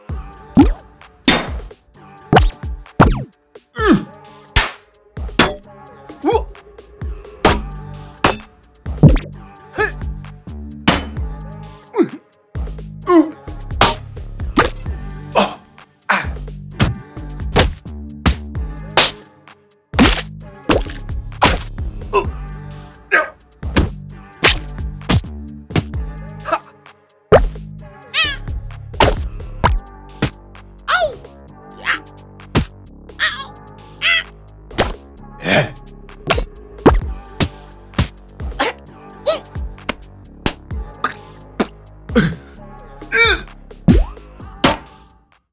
Casual Fight Hit Sounds.mp3